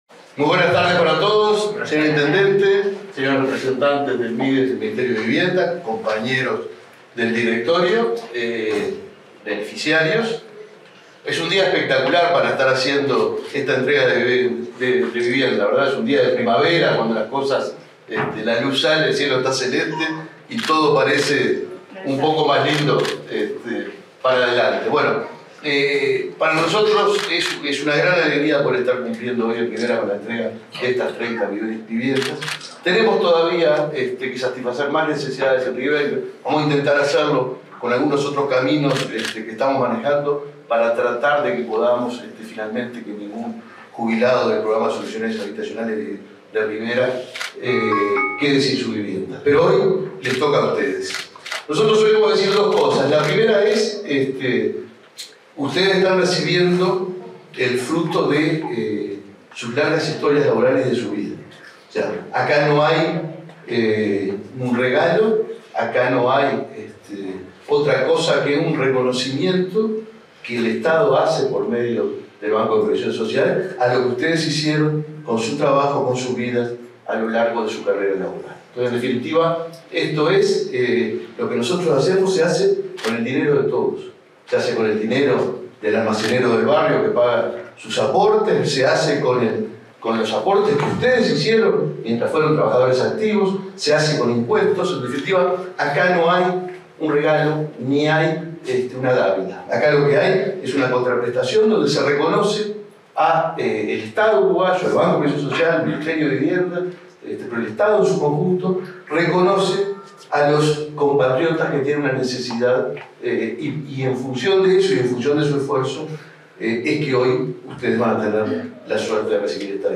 Palabras del presidente del BPS, Alfredo Cabrera
En el acto participó el presidente del organismo, Alfredo Cabrera.